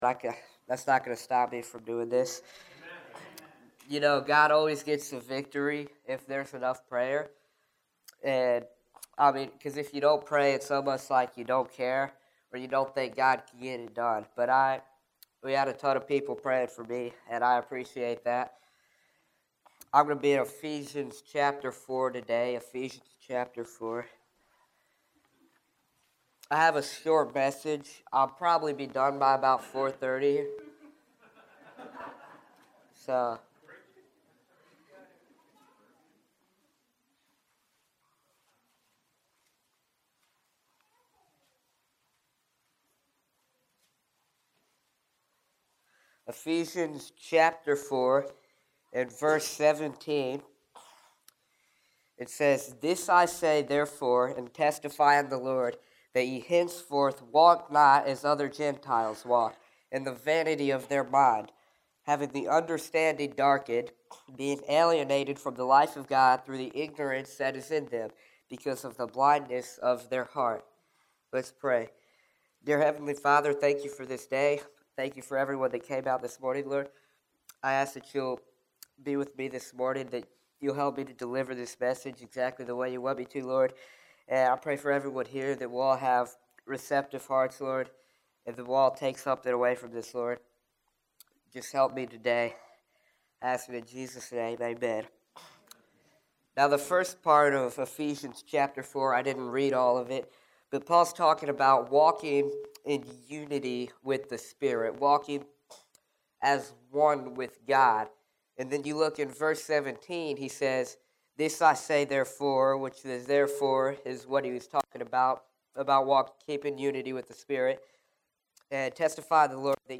Youth Sunday Sermon – Walk